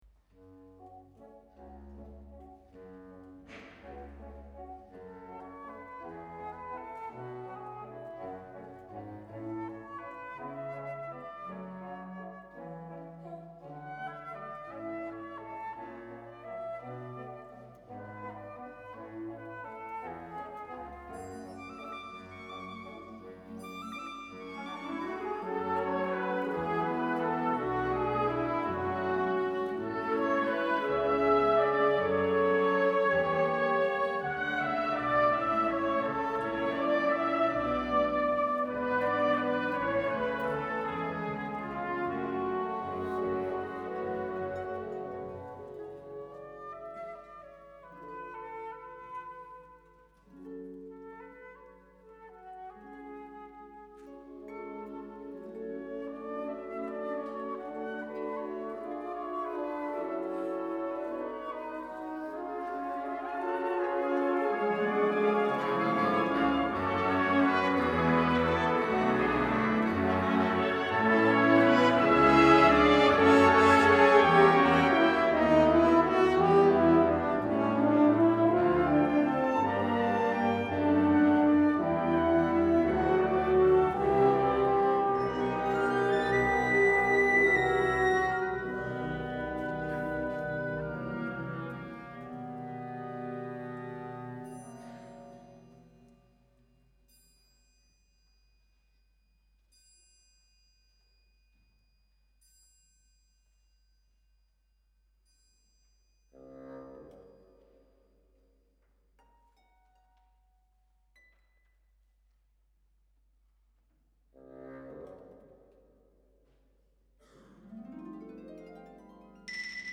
wind orchestra